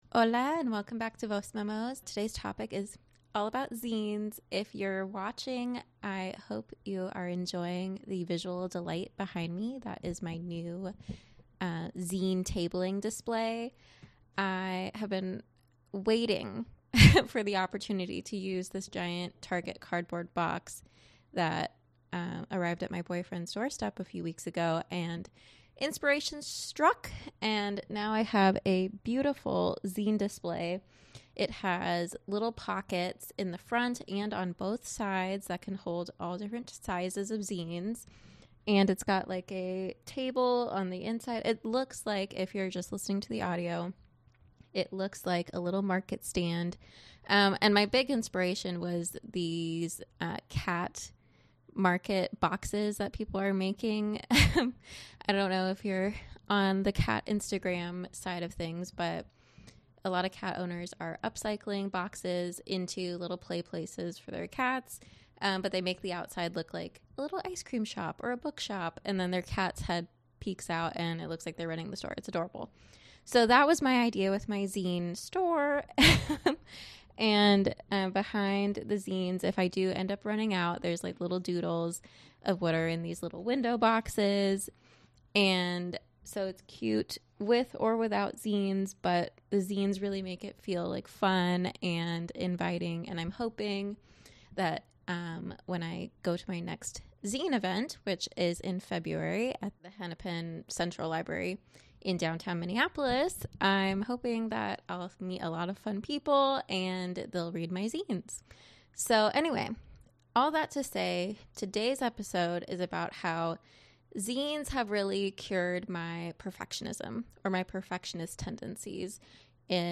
In this episode, a zine reading of my latest zine: “Do Not FWD This Zine,” my journey as a Zinester over the last year or so, and a preview of my latest zine display.